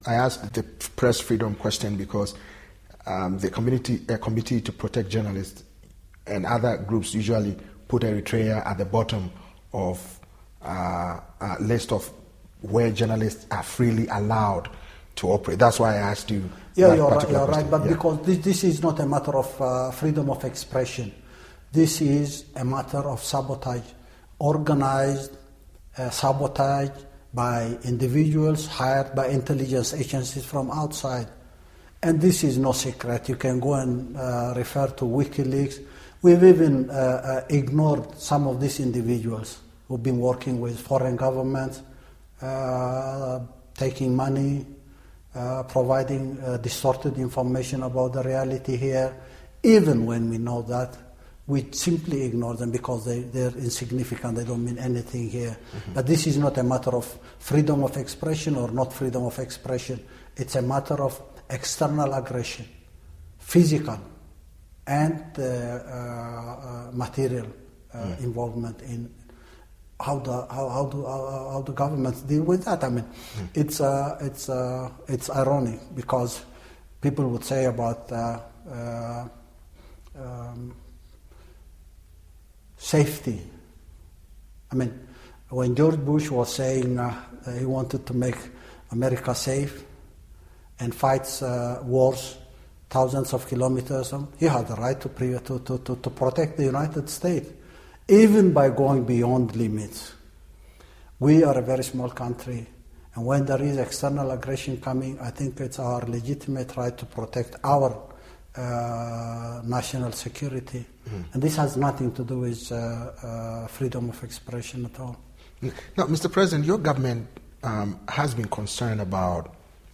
Eritrean President Isaias Afewerki interviewed by reporter